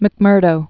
(mĭk-mûrdō)